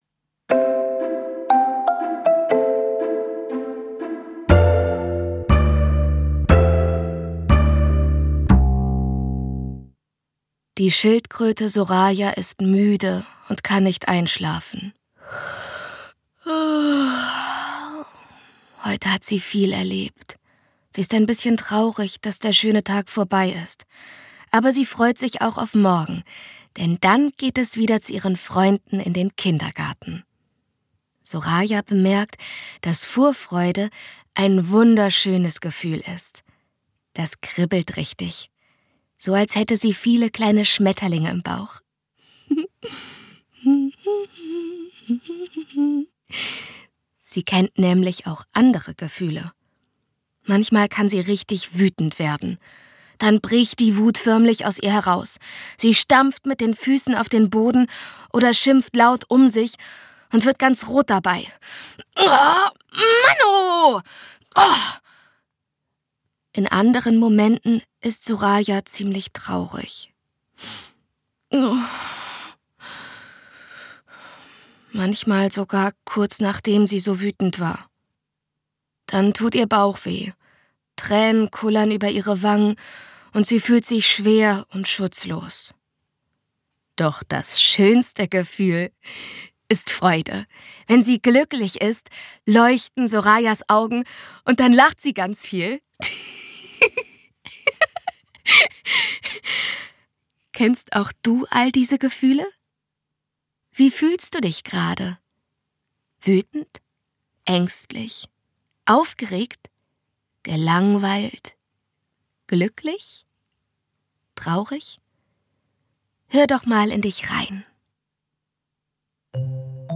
Angeregt durch atmosphärische Musik und Geräusche können kleine und große Menschen kreativ werden und ihren Gedanken eine Stimme geben.
Schlagworte Einschulung • Empowerment • Familienalltag • Familienbloggerin • gemeinsam hören • Hörbuch ab 3 • Hörbuch für Kinder • Interaktives Hörbuch • Kinderautonomie • Kinderhörbuch • Mamablog • Mamabloggerin • Mitmachhörbuch • Selbstbewusstsein stärken Kinder • Selbstliebe für Kinder • selbstwertgefühl stärken kinder • Toleranz • Vielfalt • zum Mitmachen